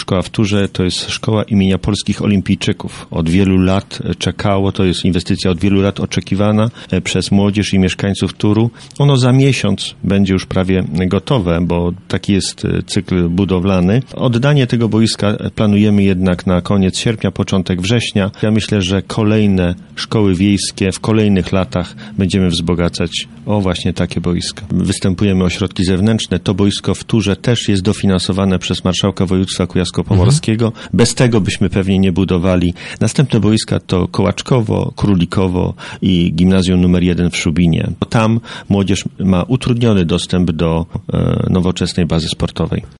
Kolejną marcową inwestycją, za równie pokaźną kwotę 344 tysięcy jest budowa boiska wielofunkcyjnego wraz z ogrodzeniem i piłkochwytem przy Szkole Podstawowej w Turze, która ruszyła w minionym tygodniu, mówi burmistrz Szubina Artur Michalak.